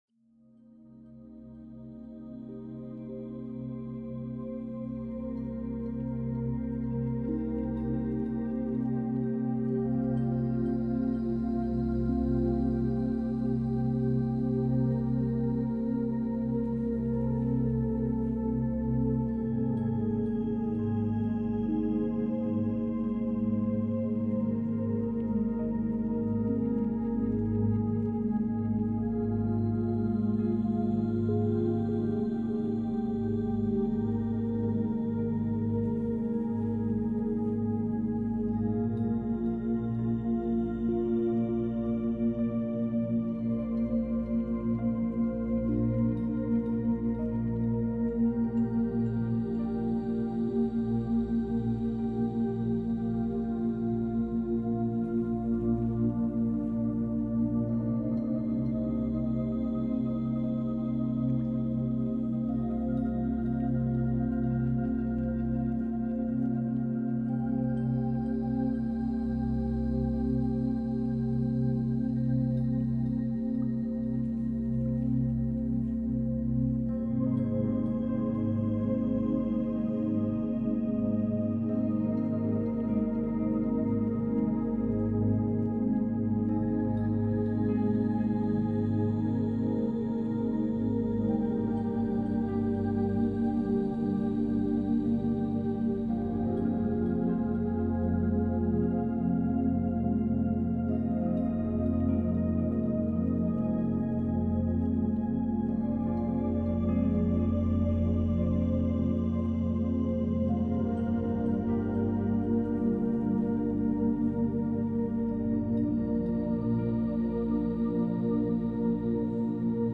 Ocean and Forest Ambience – Creative Focus for Work and Reading